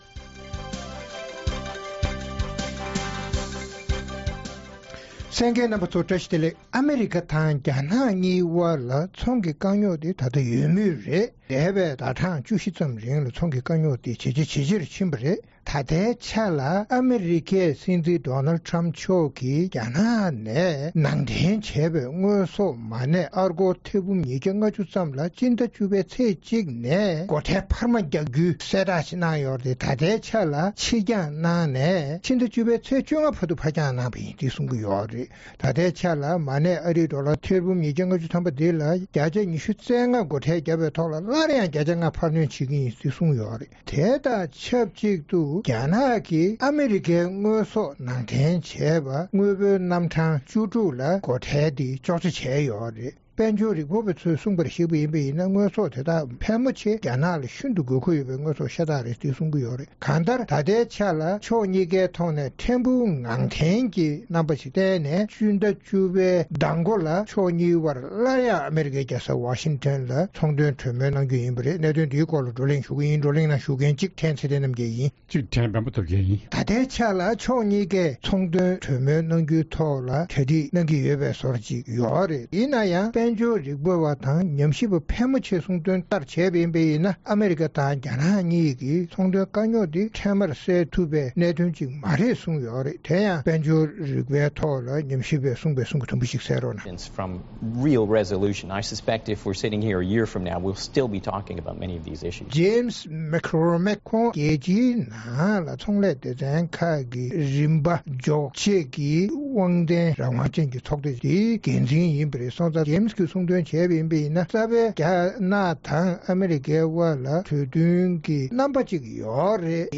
རྩོམ་སྒྲིག་པའི་གླེང་སྟེགས་ཞེས་པའི་ལེ་ཚན་ནང་། ཨ་མེ་རི་ཁ་དང་རྒྱ་ནག་གཉིས་དབར་ཚོང་དོན་དཀའ་རྙོག་བྱུང་སྟེ་ཕན་ཚུན་སྒོ་ཁྲལ་འཕར་མ་རྒྱག་བཞིན་པ་དང་། དཀའ་རྙོག་སེལ་ཐབས་སུ་གྲོས་མོལ་ལན་མང་གནང་ཡང་གྲུབ་འབྲས་ཐོན་མེད་ཀྱང་རིང་མིན་སླར་ཡང་ཕྱོགས་གཉིས་དབར་གྲོས་མོལ་གནང་འཆར་ཡོད་པ་སོགས་ཀྱི་སྐོར་རྩོམ་སྒྲིག་འགན་འཛིན་རྣམ་པའི་བགྲོ་གླེང་གནང་བ་གསན་རོགས་གནང་།